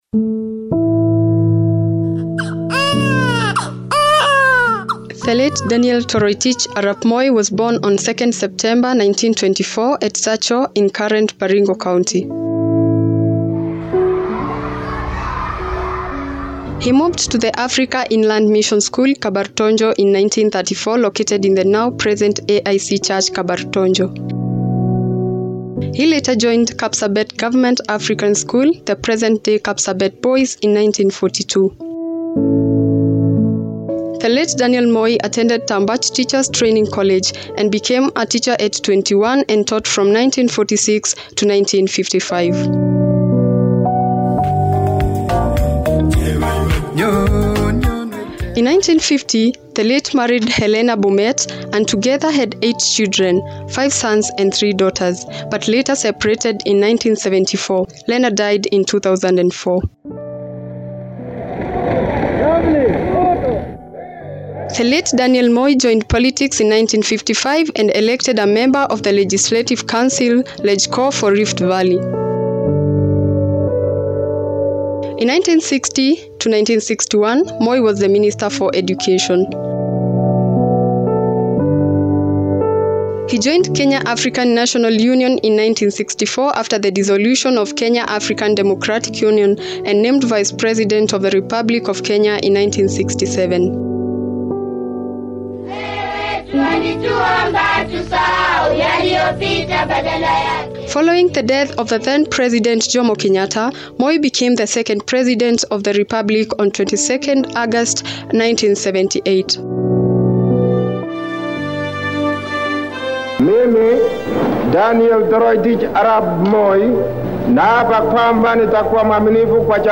RADIO DOCUMENTARY;Remembering The Late President Daniel Toroitich Arap Moi